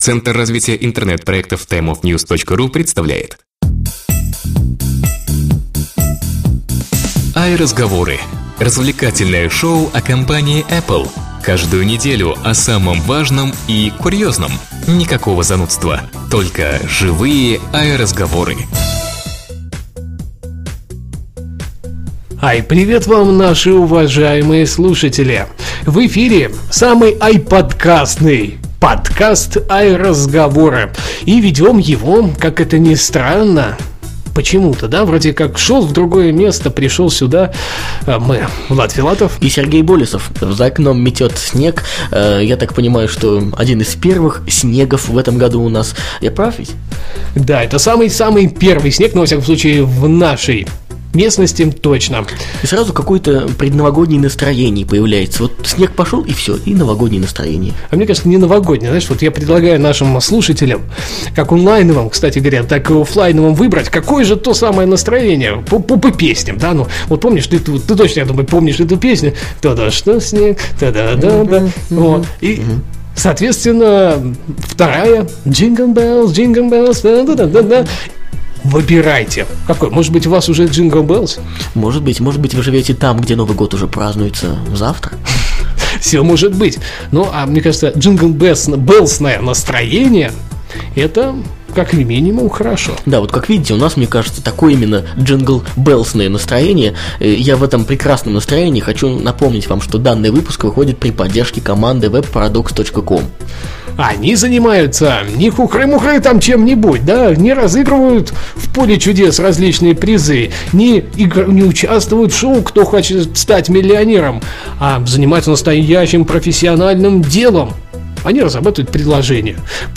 stereo Ведущие аудиошоу в свободной и непринужденной манере расскажут вам обо всех самых заметных событиях вокруг компании Apple за прошедшую неделю. Никакой начитки новостей, занудства, только живые "АйРазговоры".